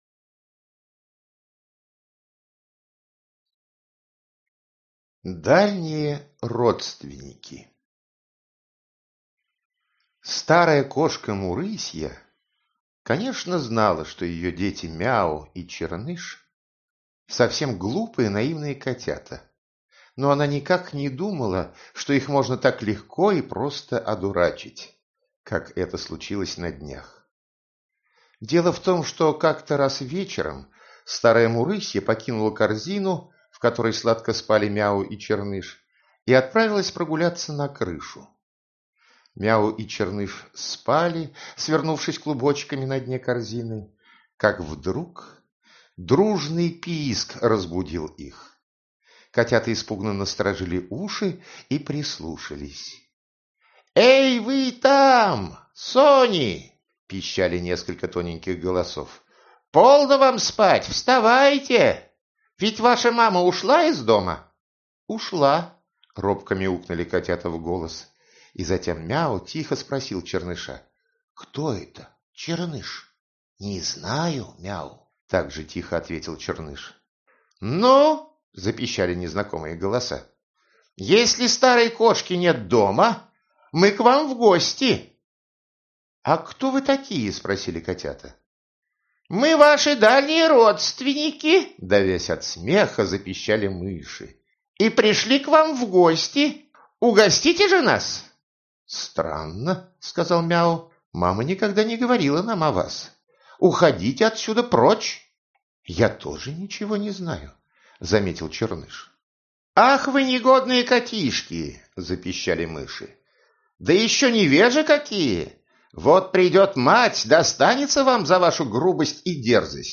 Аудиокнига Сказки Кота-Баюна | Библиотека аудиокниг